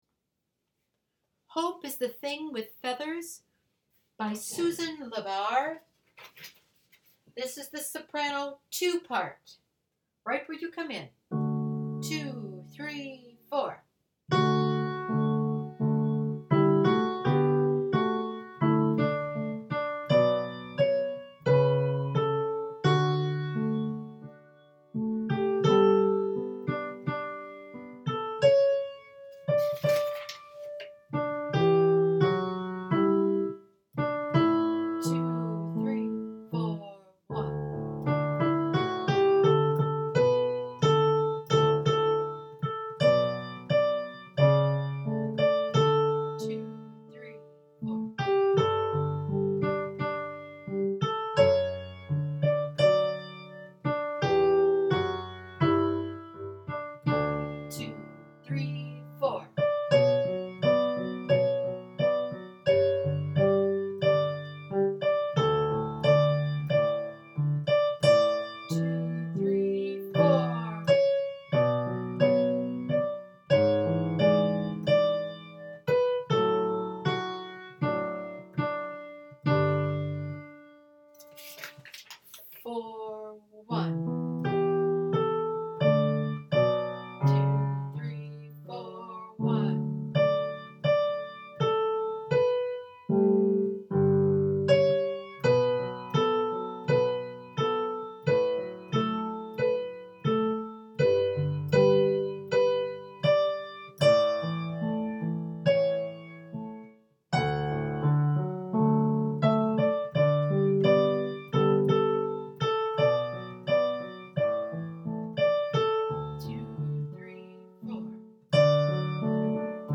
SOPRANO 2